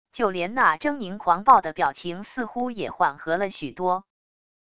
就连那狰狞狂暴的表情似乎也缓和了许多wav音频生成系统WAV Audio Player